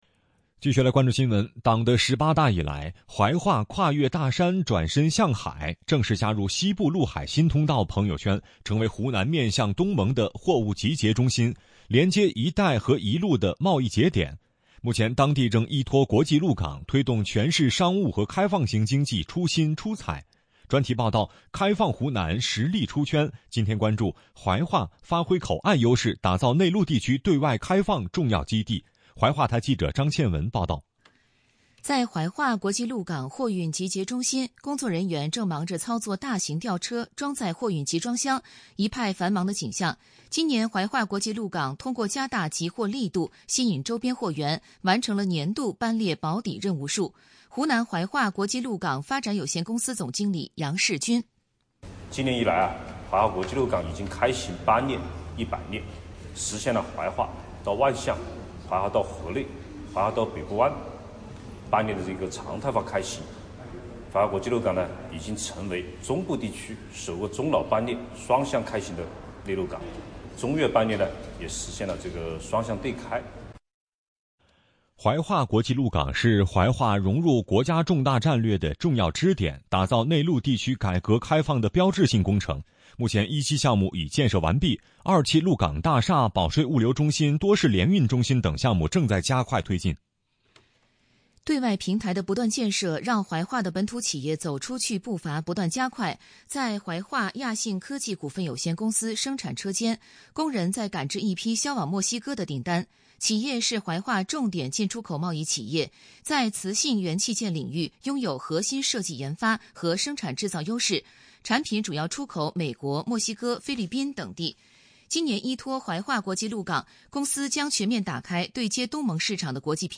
专题报道《开放湖南 实力“出圈”》，今天关注《怀化：发挥口岸优势 打造内陆地区对外开放重要基地》。